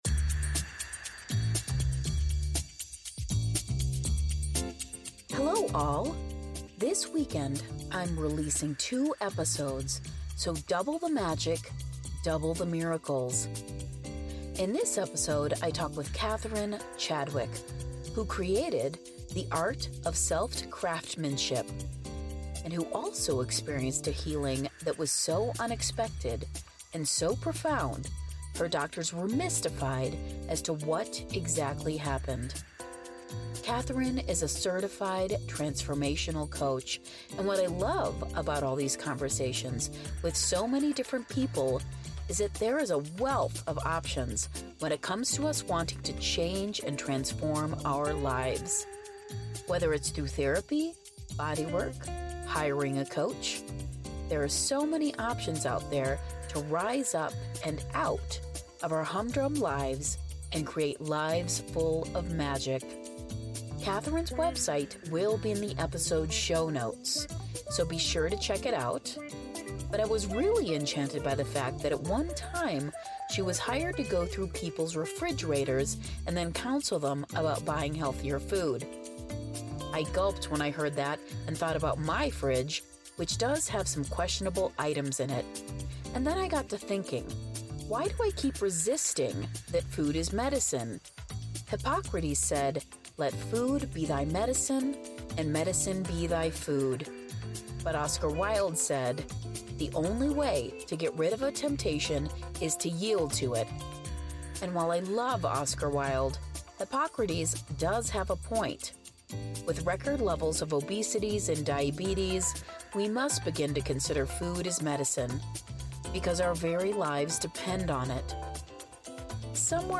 Byte Sized Blessings / The Interview